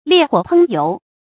烈火烹油 liè huǒ pēng yóu 成语解释 比喻声势气焰很盛。